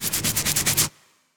scratch.wav